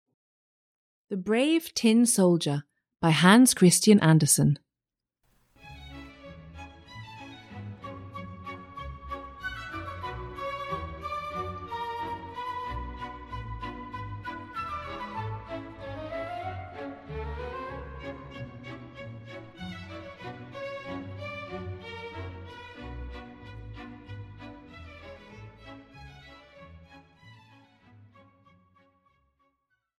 Audio knihaThe Brave Tin Soldier, a Fairy Tale (EN)
Ukázka z knihy